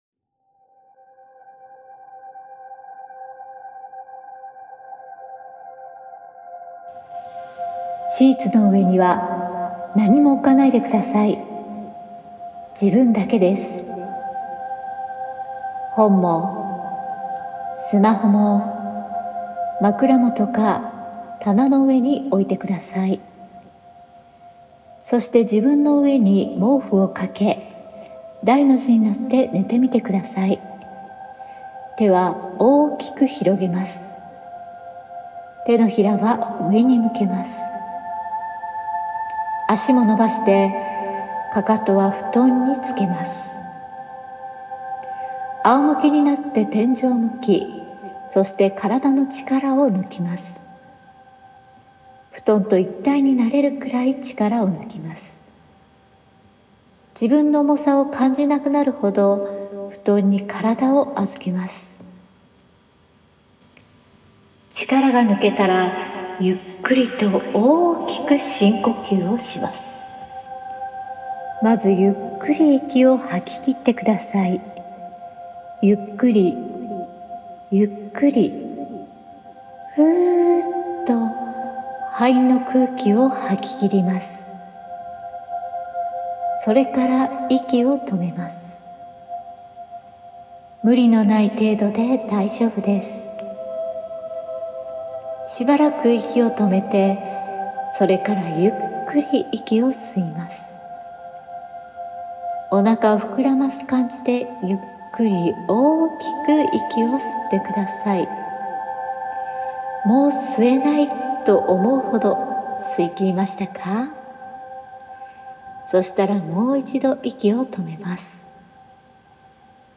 低语